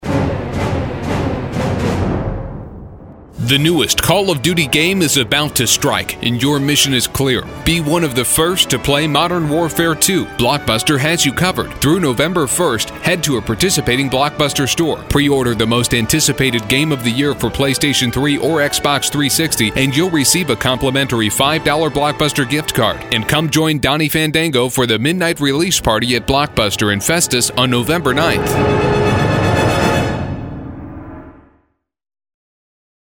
Listen Now: call of duty air check